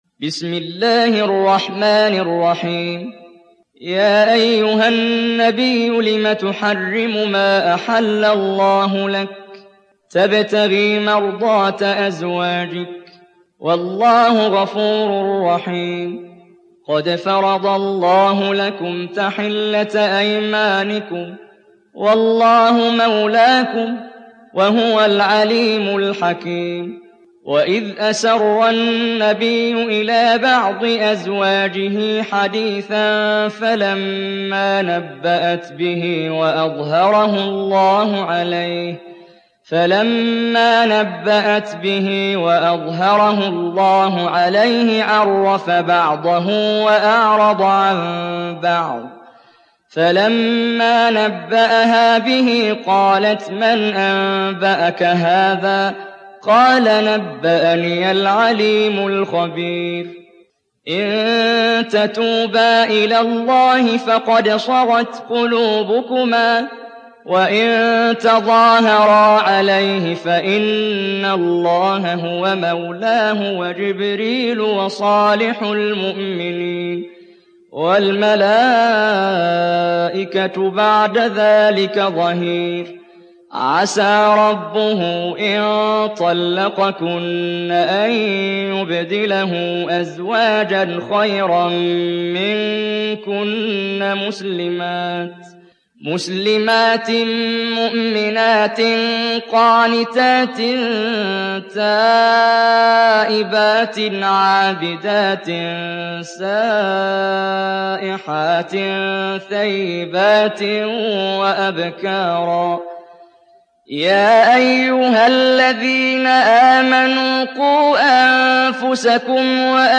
تحميل سورة التحريم mp3 بصوت محمد جبريل برواية حفص عن عاصم, تحميل استماع القرآن الكريم على الجوال mp3 كاملا بروابط مباشرة وسريعة